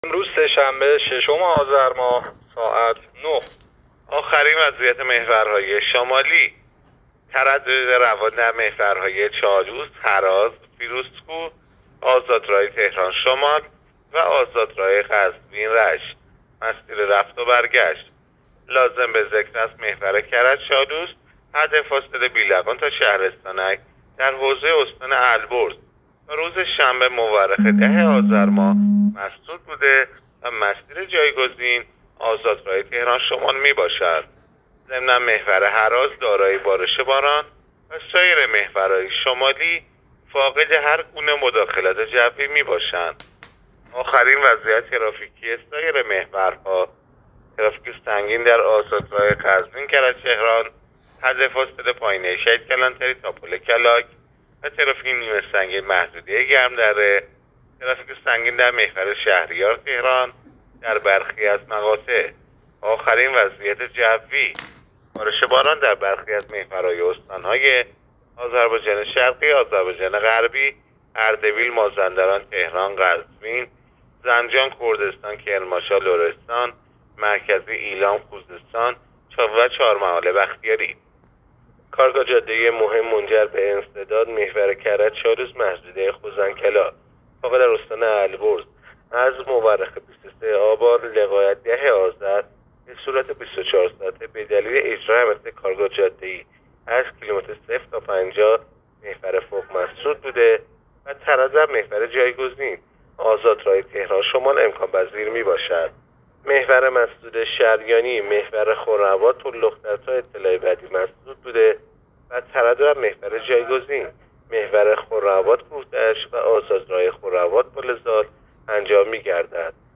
گزارش رادیو اینترنتی از آخرین وضعیت ترافیکی جاده‌ها تا ساعت ۹ ششم آذر؛